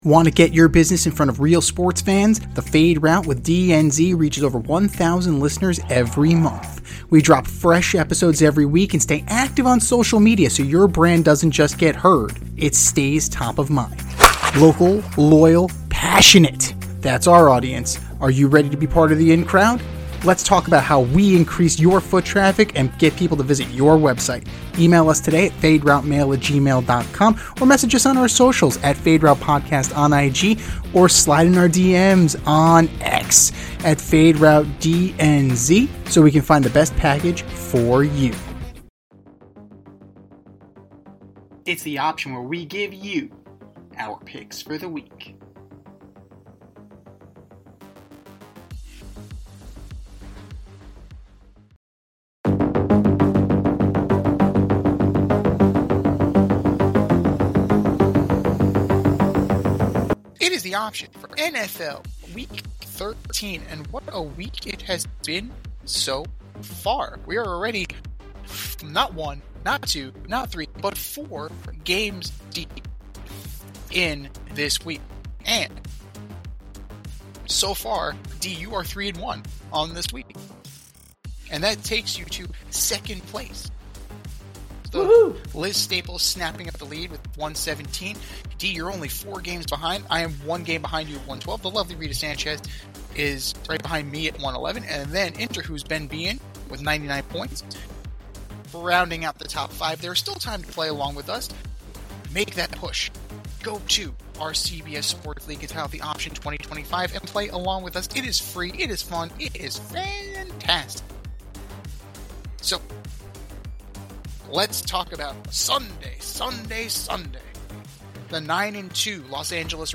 two veteran sports aficionados and lifelong friends
with wit and a touch of New York flair